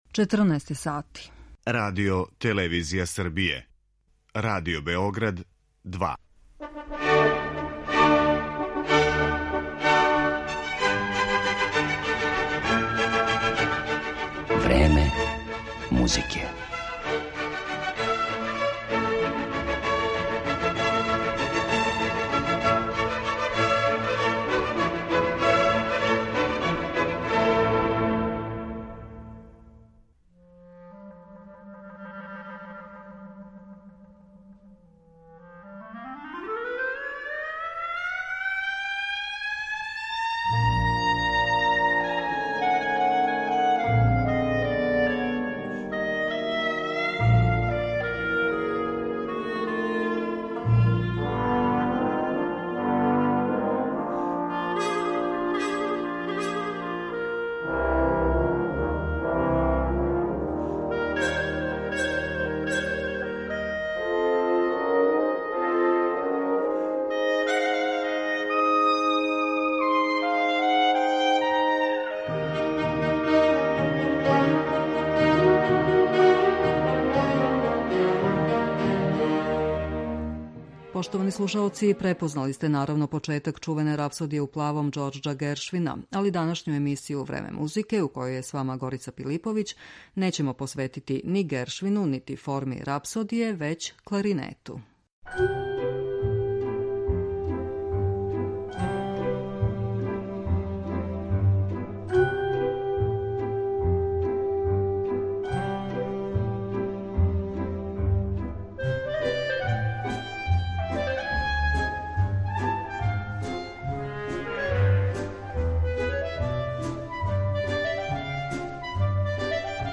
Звук кларинета
Чућемо како су топли звук кларинета у својим делима употребили Гершвин, Копланд, Боамортје, Прокофјев, Моцарт и Вебер.